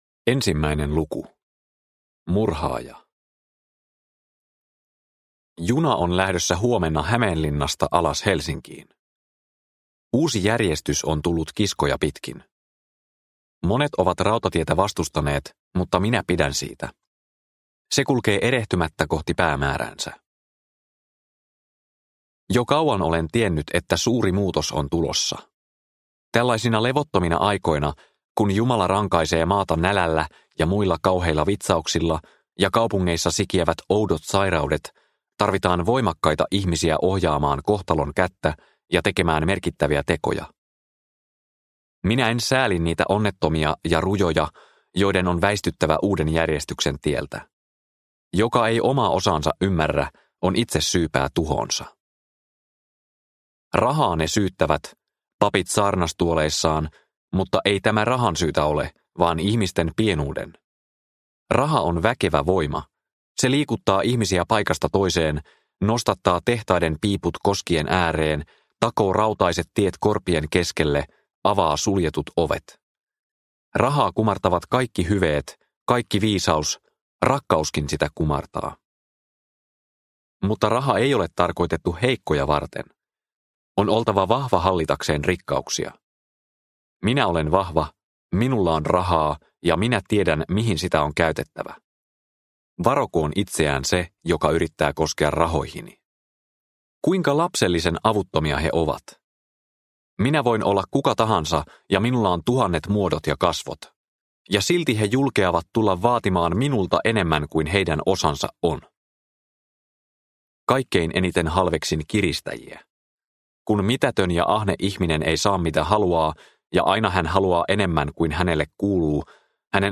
Punavuoren keisarinna – Ljudbok – Laddas ner
Historiallisen salapoliisiseikkailun ensimmäinen osa.